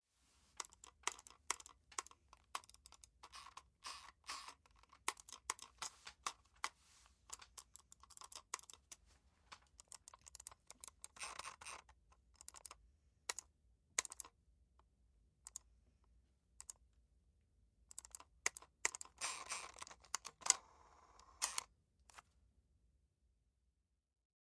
鼠标
描述：单击并滚动计算机鼠标
Tag: 滚动 计算机 鼠标 点击